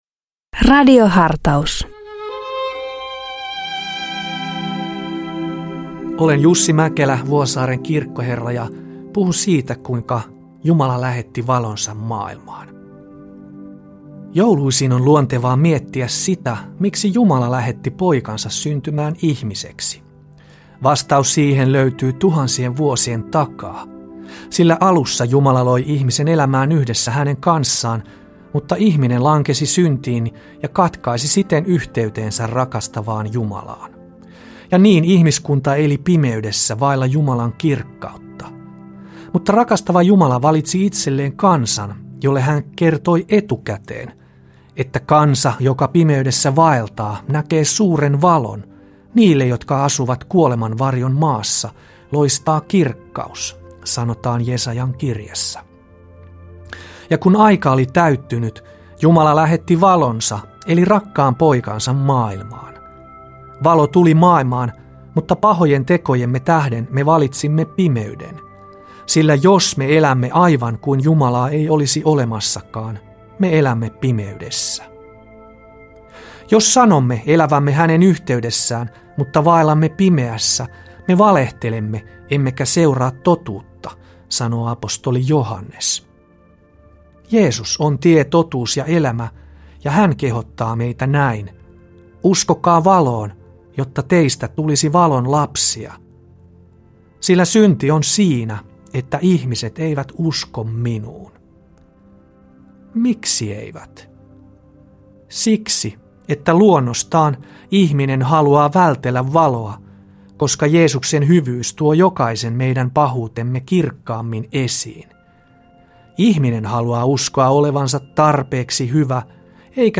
Radio Dei lähettää FM-taajuuksillaan radiohartauden joka arkiaamu kello 7.50. Radiohartaus kuullaan uusintana iltapäivällä kello 17.05. Radio Dein radiohartauksien pitäjinä kuullaan laajaa kirjoa kirkon työntekijöitä sekä maallikoita, jotka tuntevat radioilmaisun omakseen. Pääpaino on luterilaisessa kirkossa, mutta myös muita maamme kristillisen perinteen edustajia kuullaan hartauspuhujina.